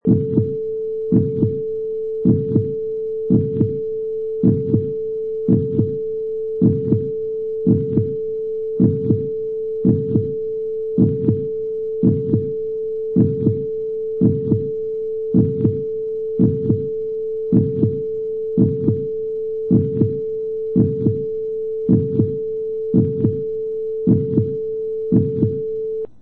Sound files: Heartbeat 1
Steady heartbeat
Product Info: 48k 24bit Stereo
Category: Human / Physical Sounds - Heartbeats
Try preview above (pink tone added for copyright).
Heartbeat_1.mp3